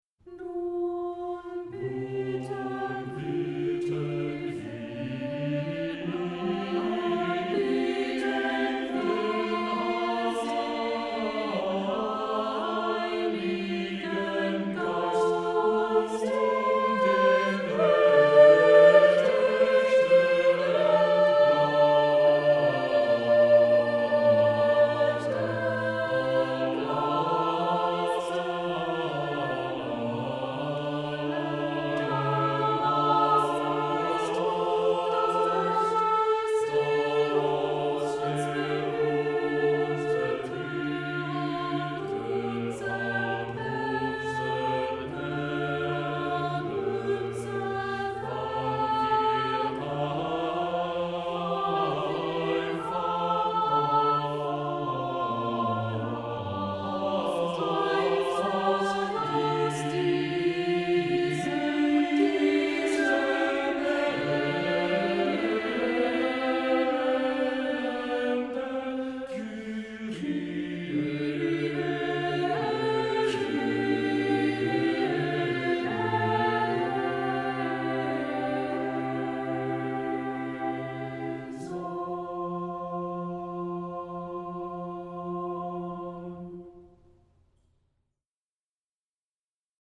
Accompaniment:      A Cappella, Cello
Music Category:      Early Music
Bass part may be sung or played by cello or organ.